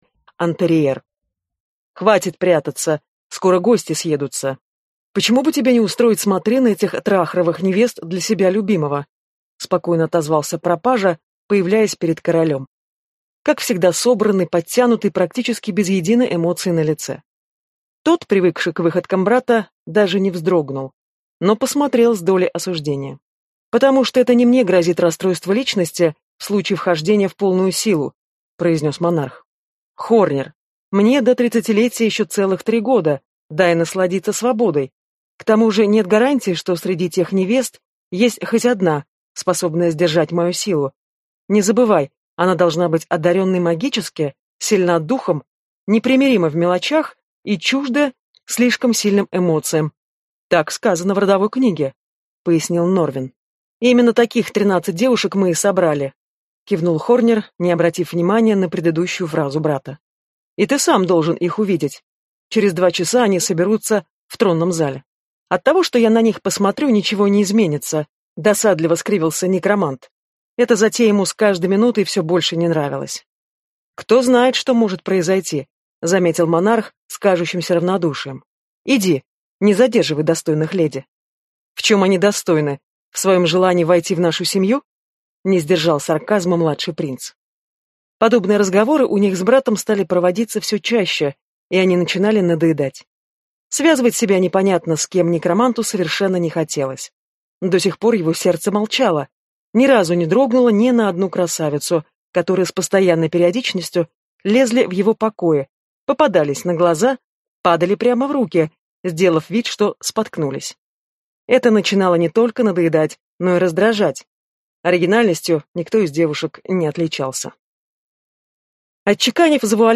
Аудиокнига Склеп в наследство | Библиотека аудиокниг
Прослушать и бесплатно скачать фрагмент аудиокниги